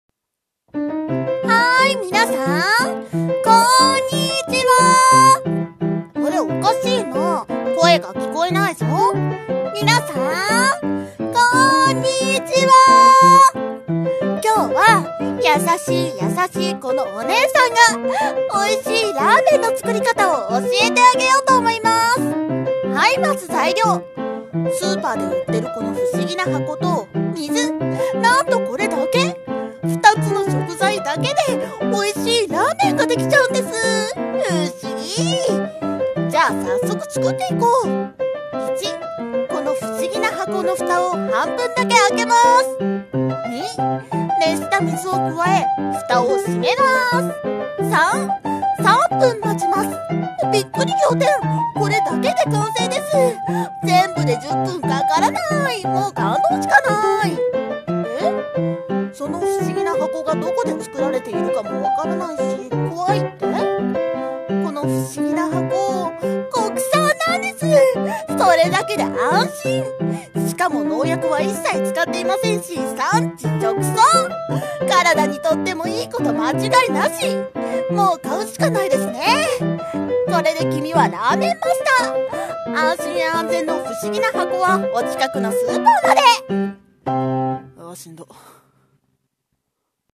声劇】美味しいラーメンの作り方